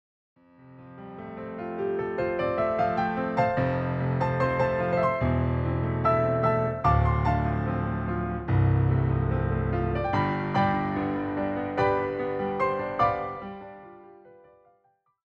translate to a solo piano setting.